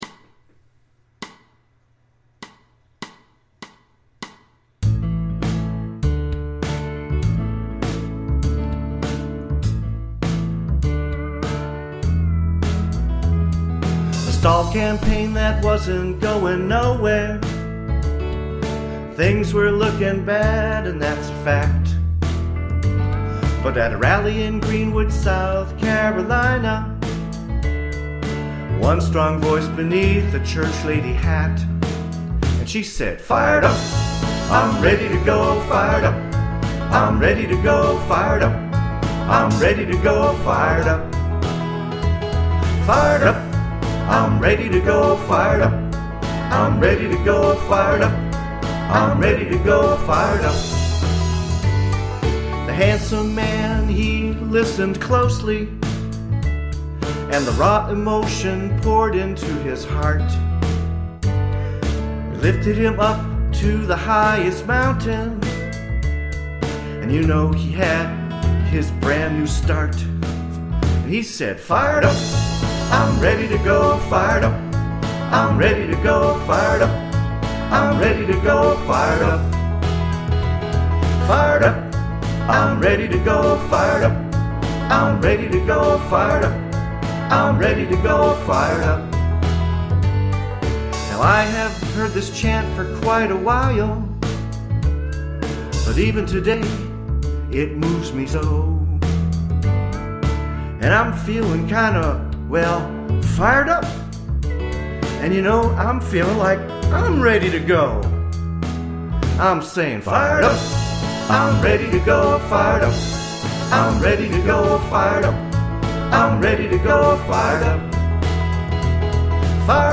This is a real rough recording.